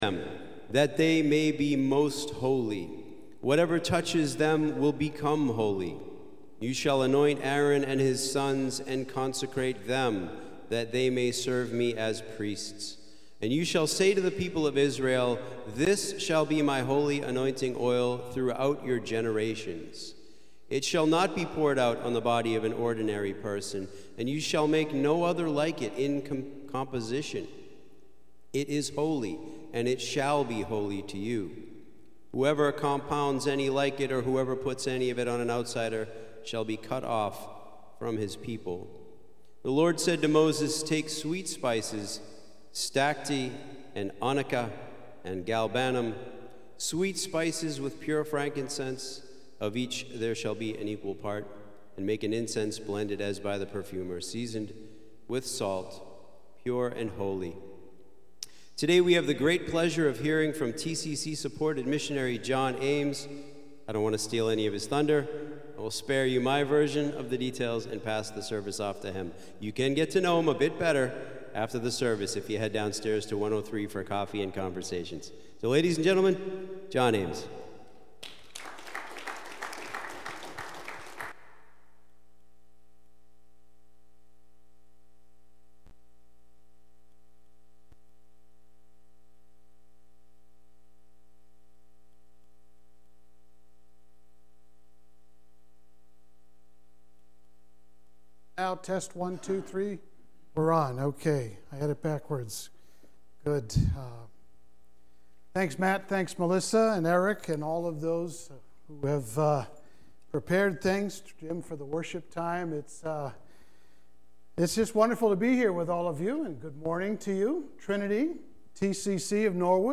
Sunday-Worship-main-82023.mp3